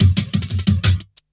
17 LOOP10 -L.wav